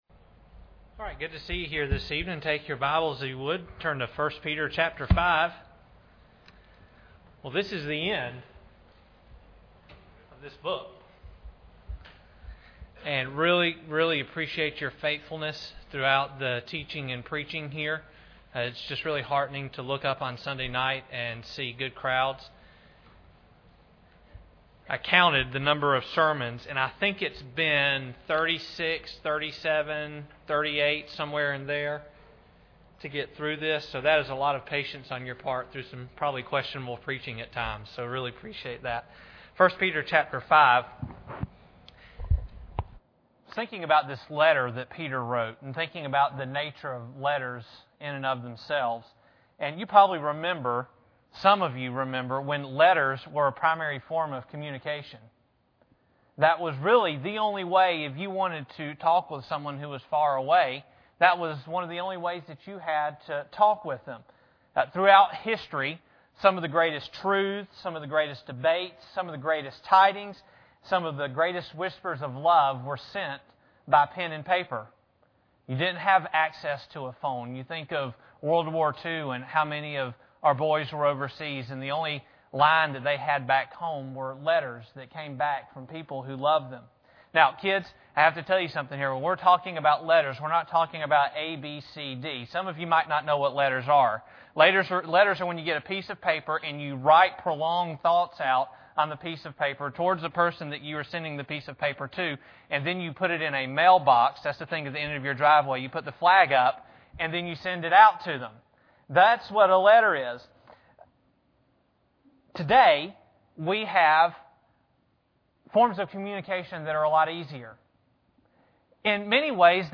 1 Peter 5:12-14 Service Type: Sunday Evening Bible Text